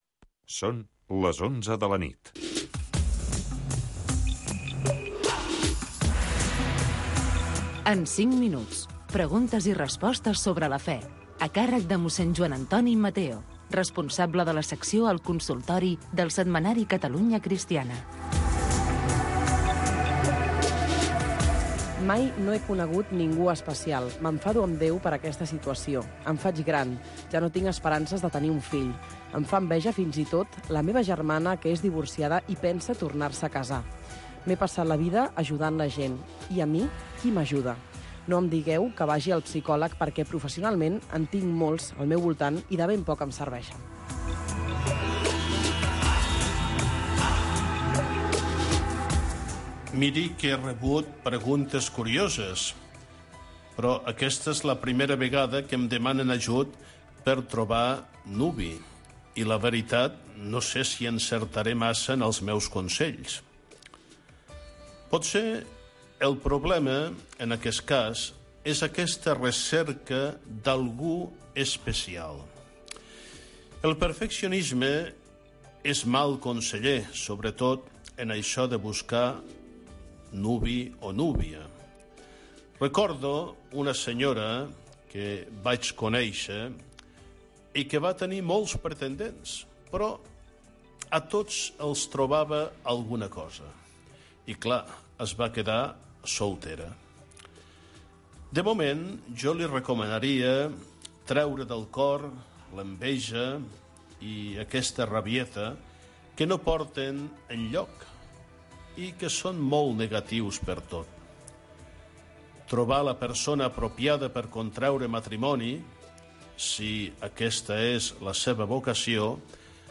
Espai on els oients poden fer consultes de tipus religiós